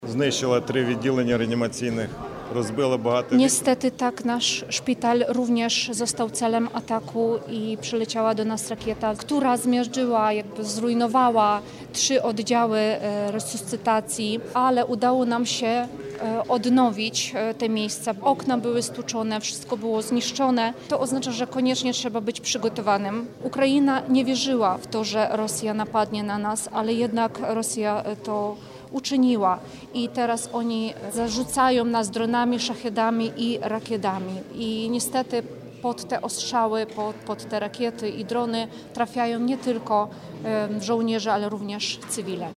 O tym rozmawiali eksperci podczas konferencji na Politechnice Wrocławskiej pod hasłem: „Bezpieczeństwo i funkcjonowanie podmiotów leczniczych w sytuacjach kryzysowych na podstawie doświadczeń Ukrainy. Zabezpieczenie medyczne wojsk na potrzeby obronne w Polsce”.